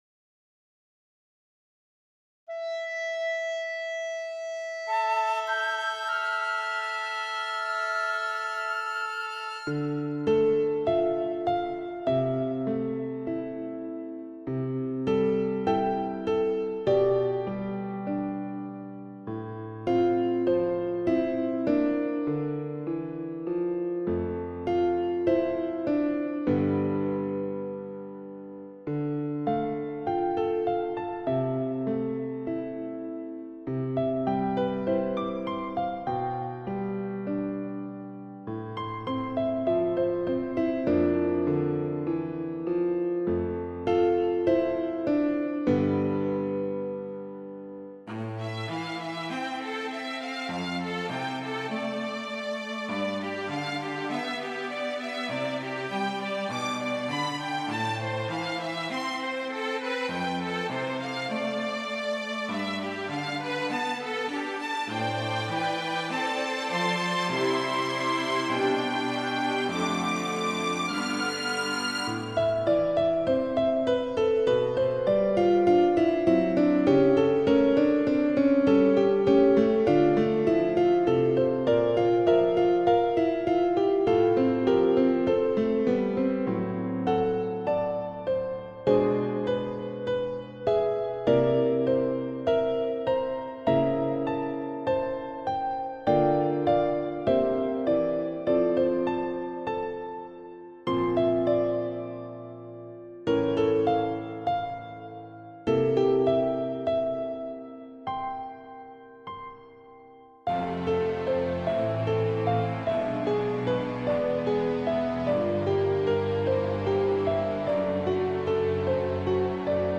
As the oboe opens the piece, so do you slowly open you eyes.
The piano line is peaceful but temporary.